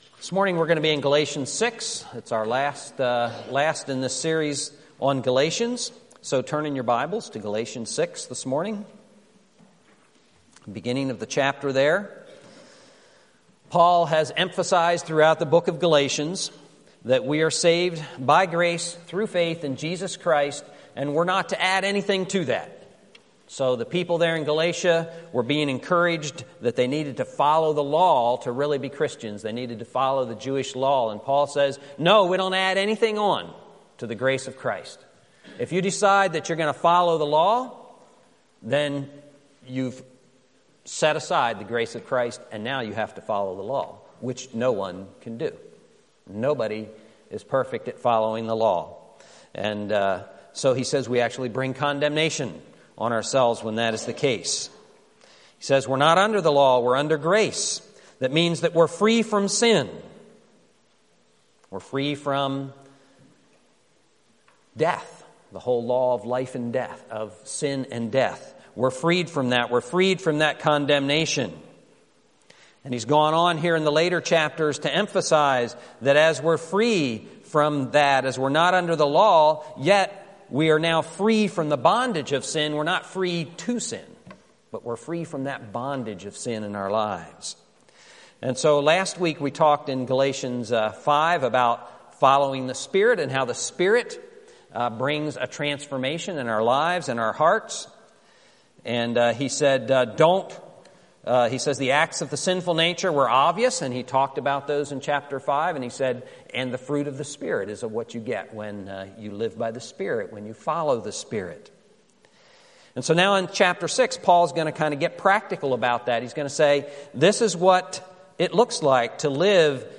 Passage: Galatians 6 Service Type: Sunday Morning